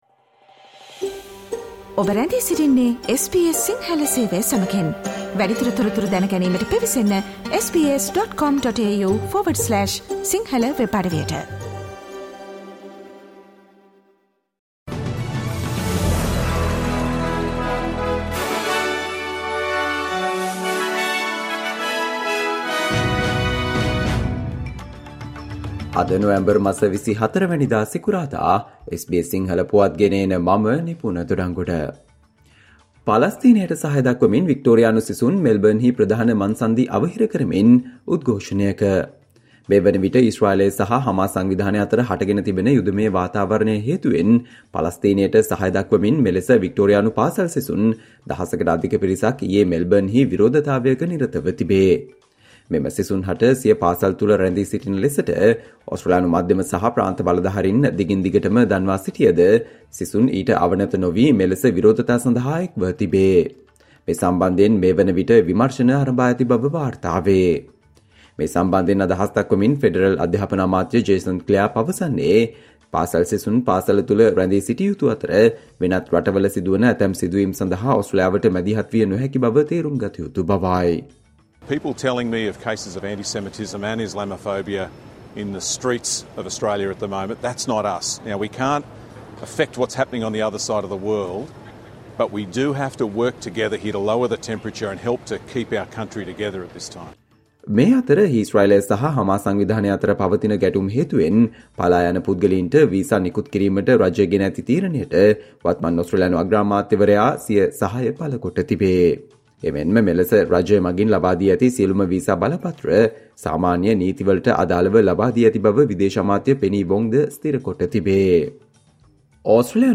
Australia news in Sinhala, foreign and sports news in brief - listen, Friday 24 November 2023 SBS Sinhala Radio News Flash